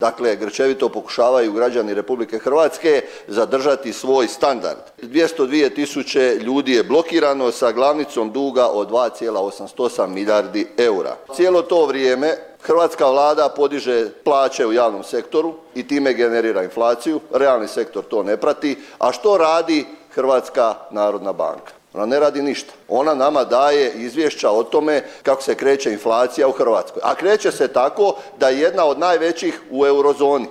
Stanku je zatražio i zastupnik MOST-a Ivica Ledenko upozorivši kako su prema podacima iz studenoga 2024. godine hrvatski građani zaduženi 24 milijarde eura, od čega gotovo devet milijardi u kreditima koji su nenamjenski.